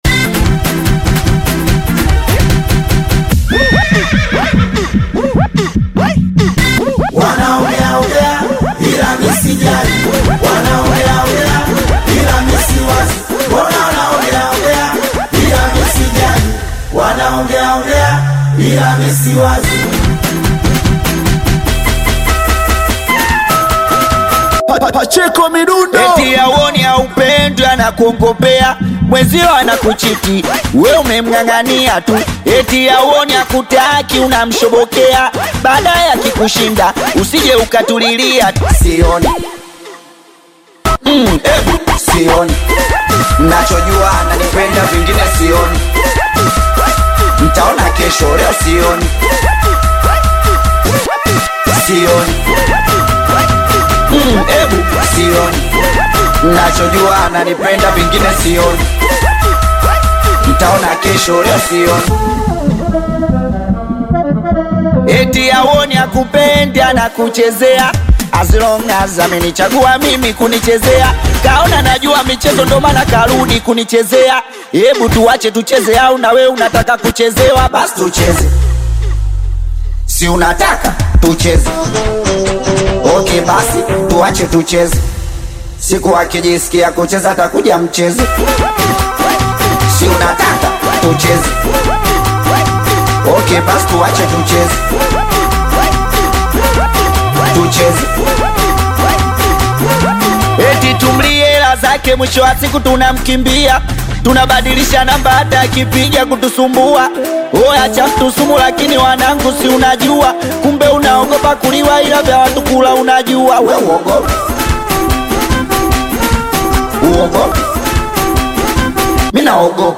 Singeli music track
Bongo Flava
Singeli song